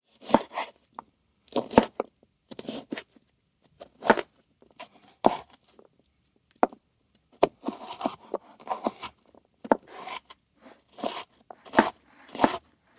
Звуки моркови
Звук нарезки кухонным ножом